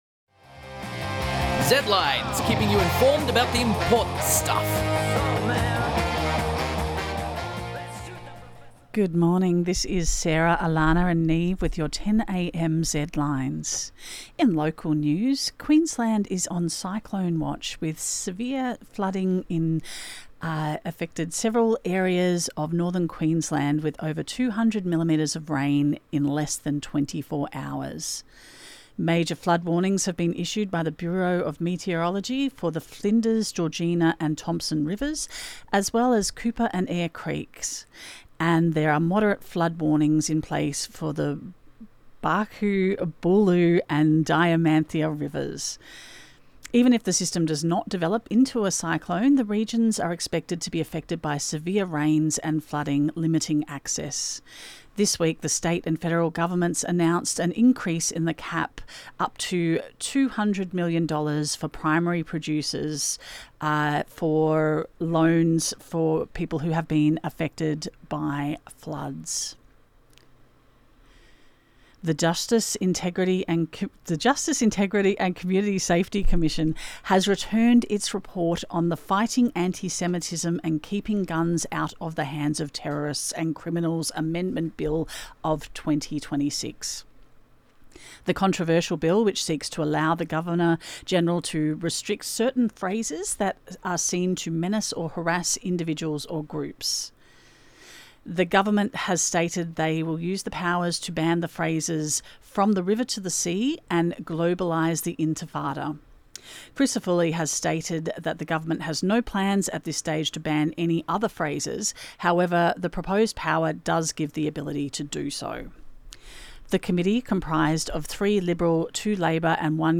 Zedlines Bulletin